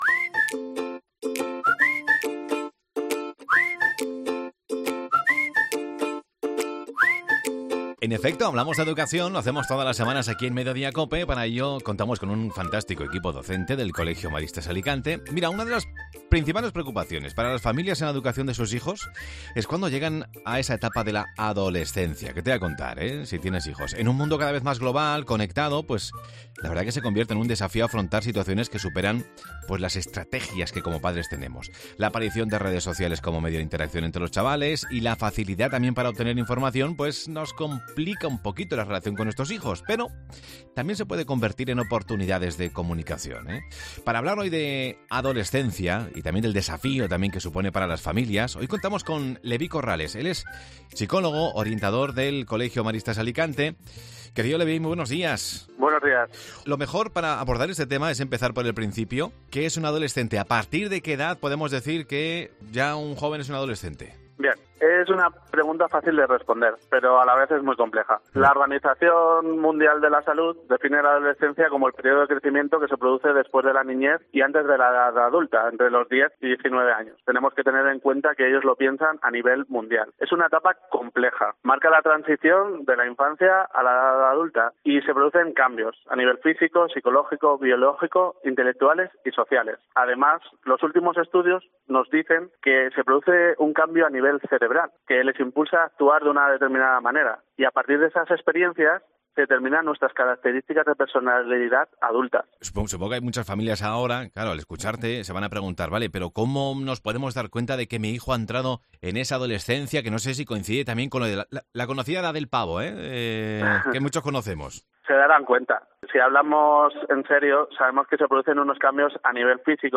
La etapa de la adolescencia se convierte en un desafío pero también en oportunidades para las familias. Escucha la entrevista